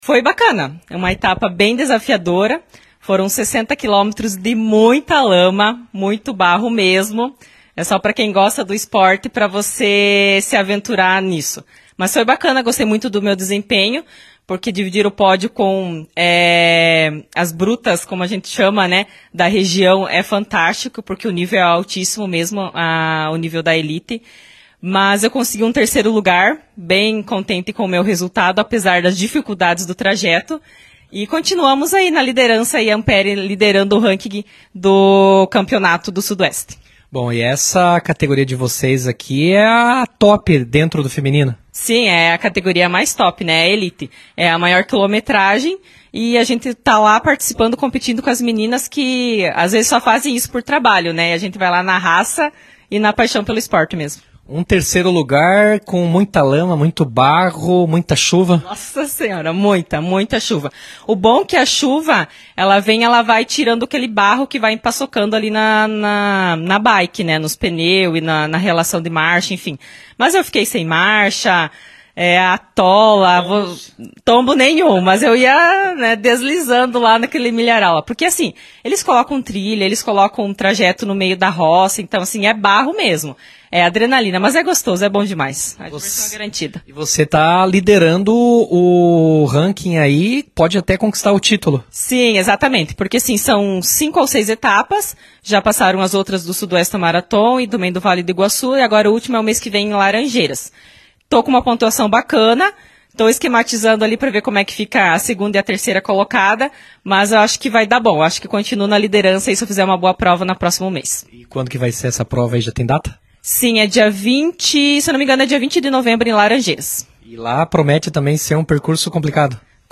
conversou com nossa reportagem e falou da alegria com o resultado obtido na prova.